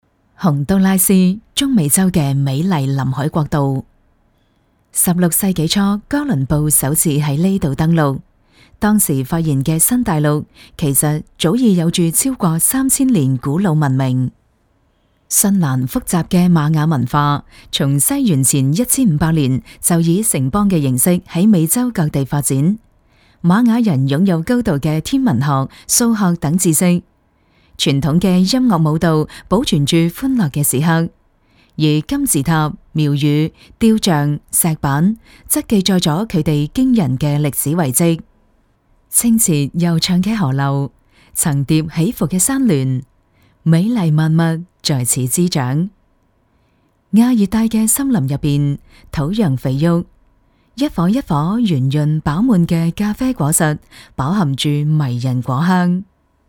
粤语青年沉稳 、积极向上 、亲切甜美 、女专题片 、宣传片 、工程介绍 、绘本故事 、动漫动画游戏影视 、120元/分钟女粤135 广式粤语港式粤语 专题片商场广播 沉稳|积极向上|亲切甜美